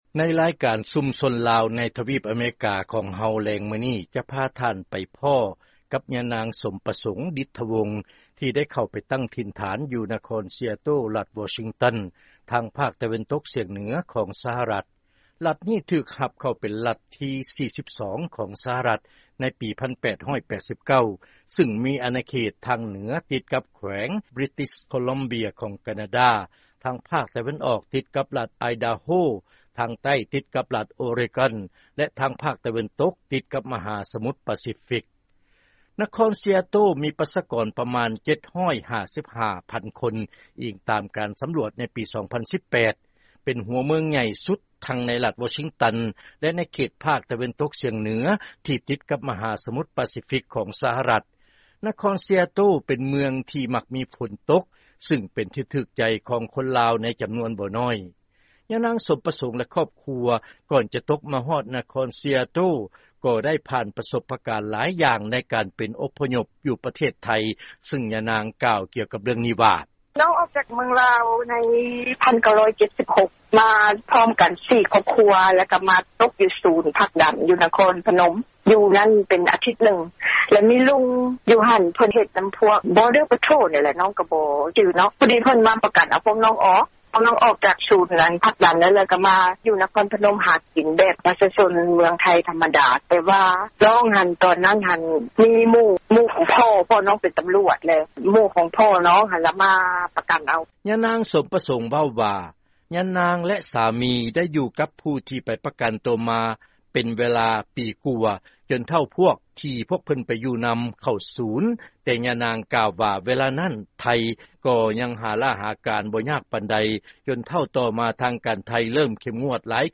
ເຊີນຟັງລາຍງານ ຊຸມຊົນລາວໃນນະຄອນຊີອາໂຕ້ ລັດວໍຊິງຕັນ